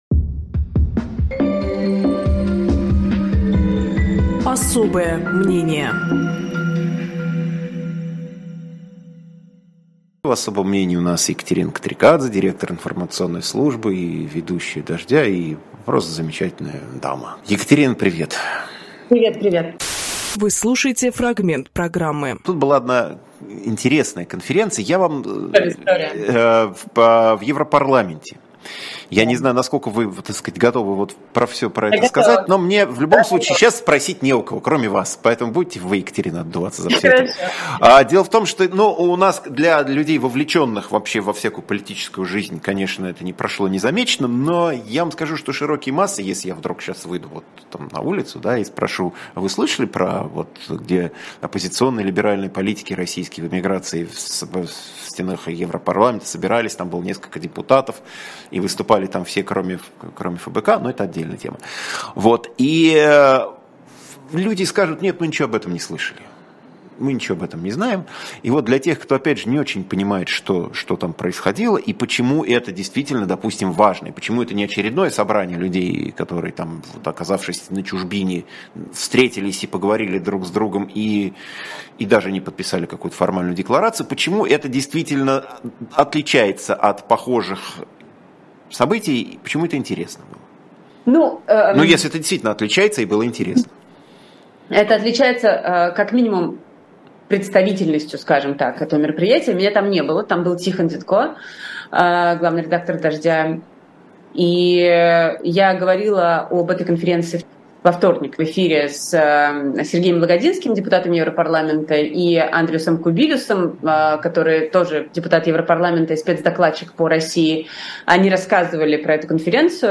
Фрагмент эфира от 08.06